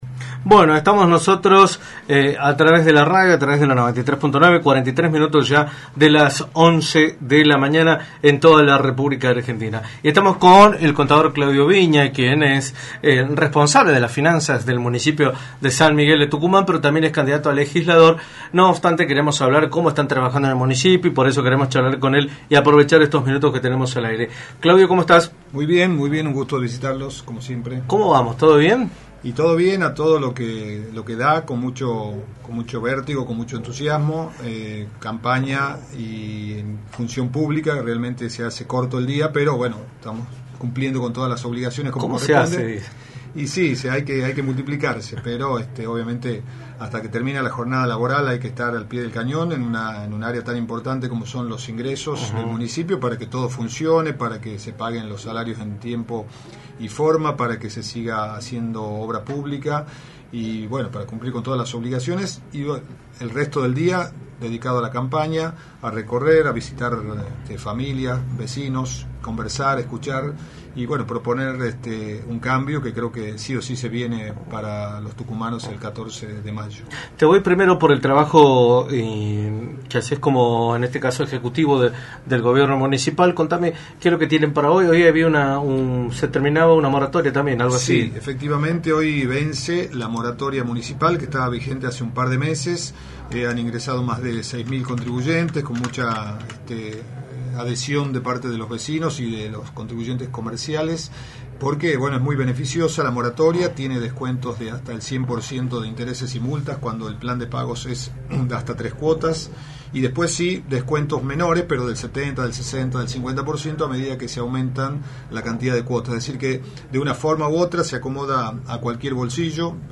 Claudio Viña, responsable de las finanzas de la Municipalidad de San Miguel de Tucumán y candidato a Legislador, visitó los estudios de Radio del Plata Tucumán, por la 93.9, y abordó el escenario político y electoral de la provincia, de cara a las elecciones del próximo 14 de mayo.
entrevista